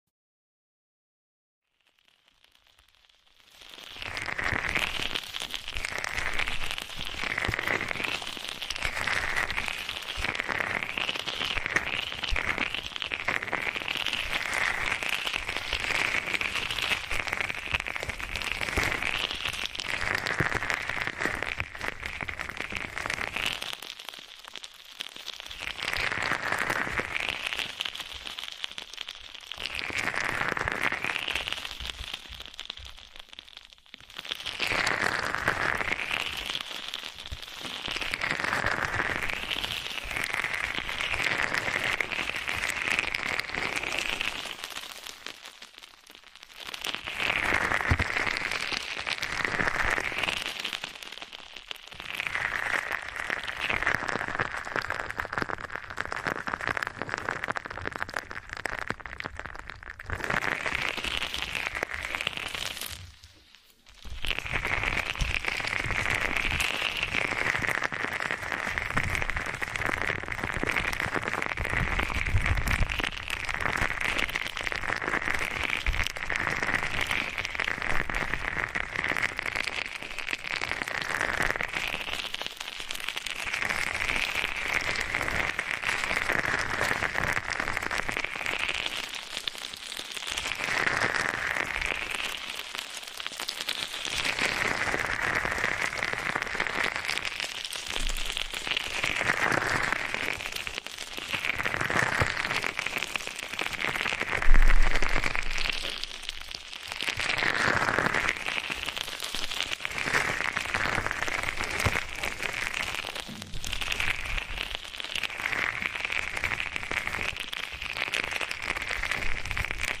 ASMR para dormir - Esponjita Konjac ✨